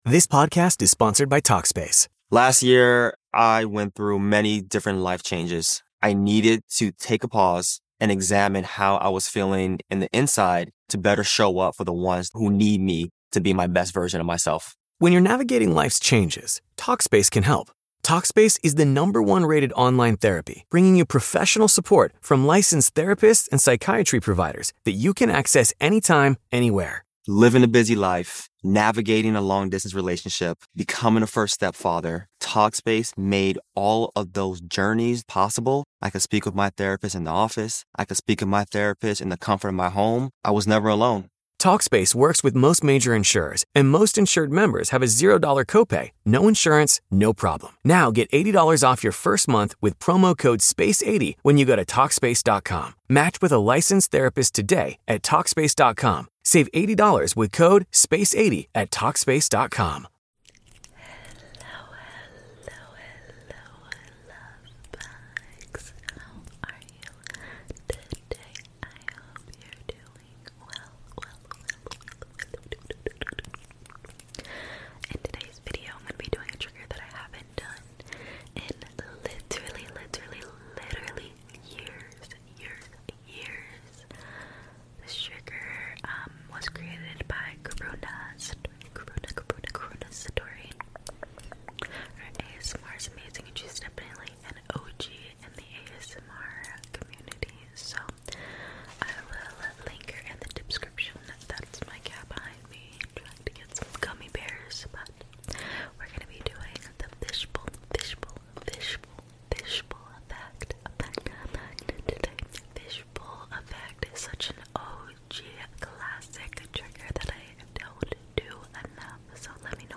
ASMR FISHBOWL EFFECT TRIGGER 🐟🫧